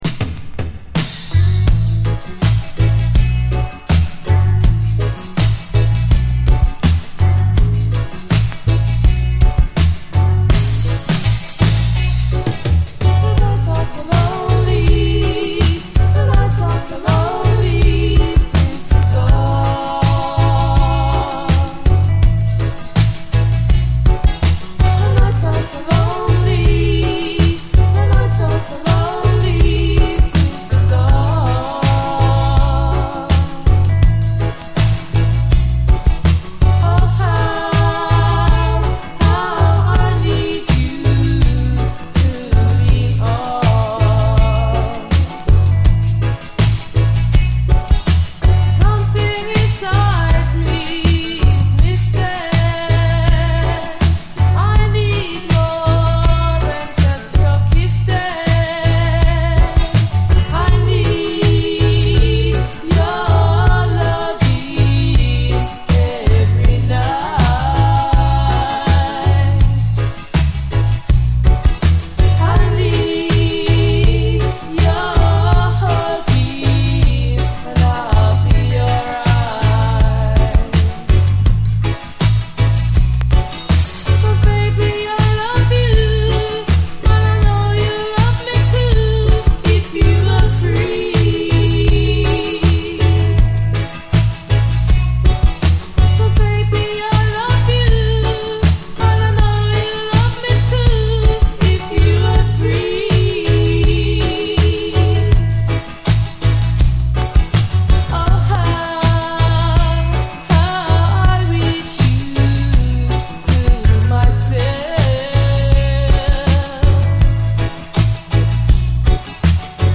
REGGAE
儚げなコーラスにノックアウトされる、最高UKラヴァーズ！